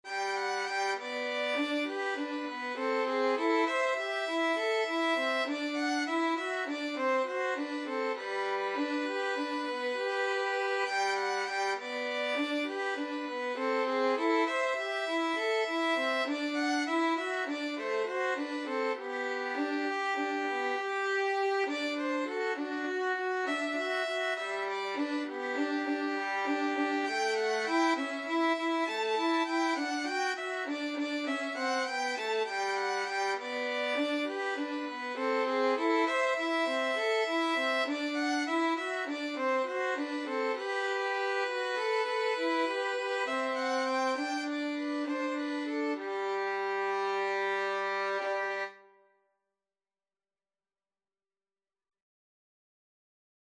Violin 1Violin 2
Beautiful Dreamer is a parlor song by Stephen Foster.
G major (Sounding Pitch) (View more G major Music for Violin Duet )
9/8 (View more 9/8 Music)
Moderato
Violin Duet  (View more Intermediate Violin Duet Music)